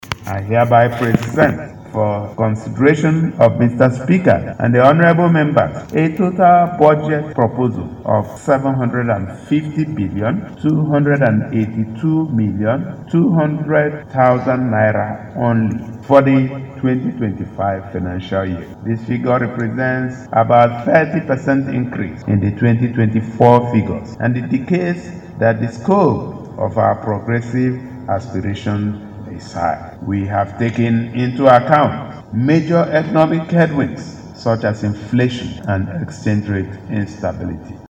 Presenting the estimate tagged “Budget of sustained momentum” at the State House of Assembly Governor Alex Otti said his government took into account major economic headwinds such as inflation and exchange rate instability.